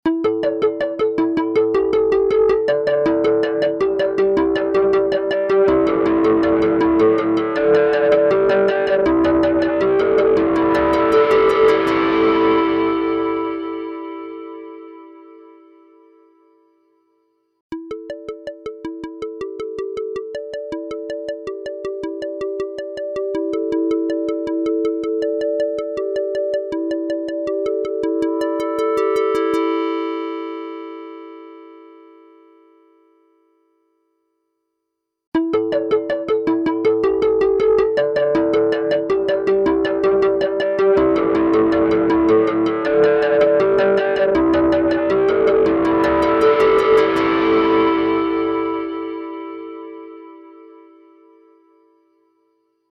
豊潤なリバーブと、引き裂くようなディストーション
MangledVerb | Synth | Preset: Wobbly Comb
MangledVerb-Synth-Preset-Wobbly-Comb.mp3